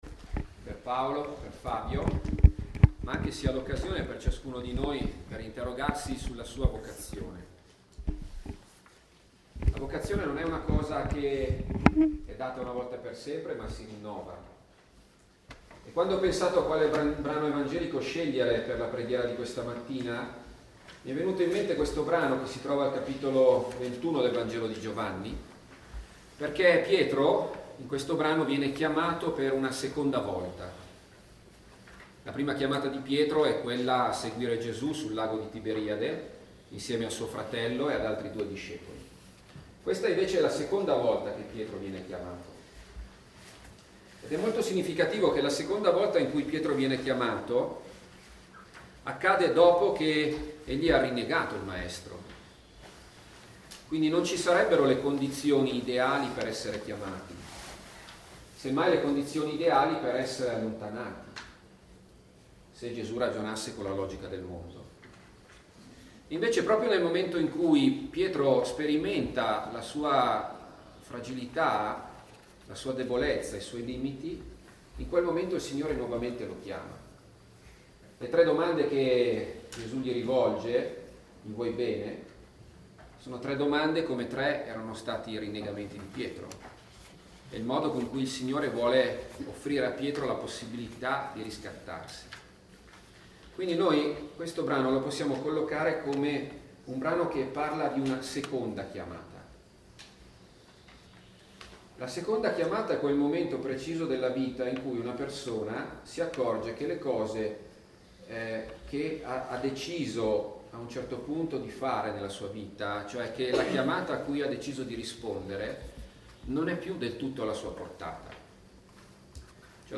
Ritiro comunitario a Barzio – Parrocchia di Roveleto
LECTIO